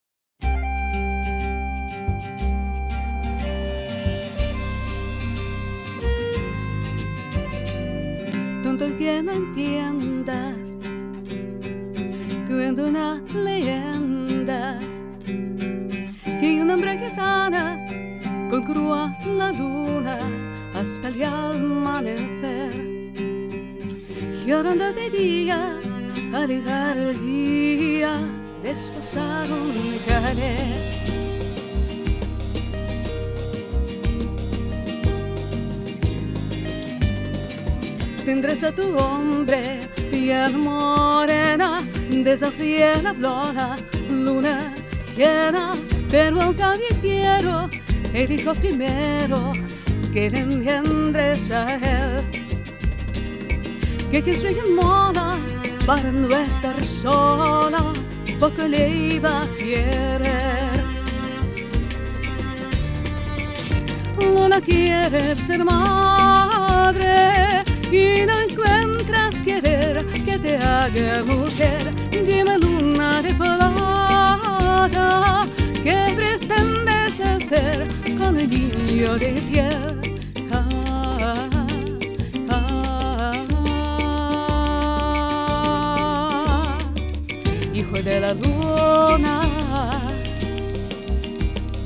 Небольшое сравнение Neumann CMV563 и Союз 023 Bomblet. Даже не то, чтобы сравнение, а просто девушка спела сначала в один, потом в другой.
Пред UA 6176, файлы голоса совершенно без обработки.